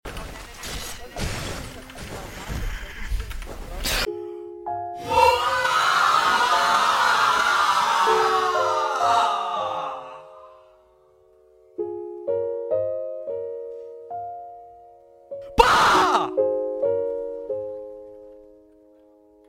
scream of agony and despair sound effects free download